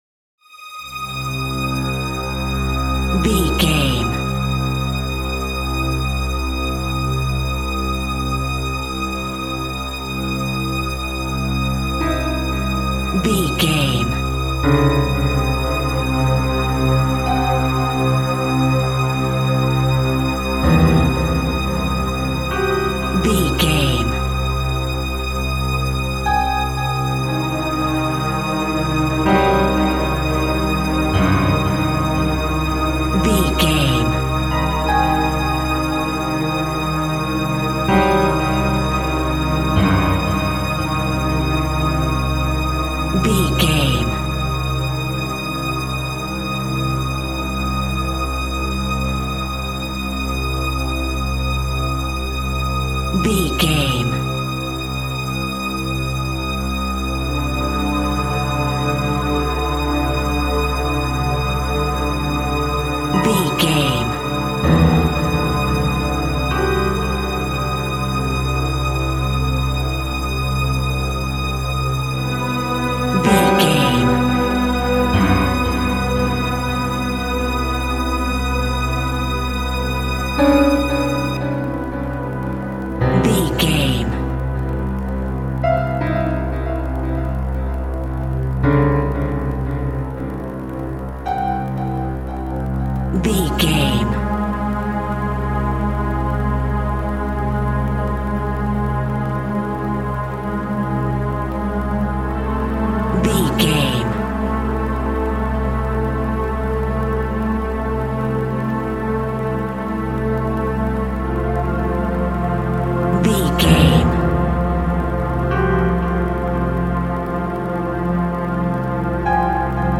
Aeolian/Minor
tension
ominous
dark
suspense
haunting
eerie
strings
piano
synthesizer
pads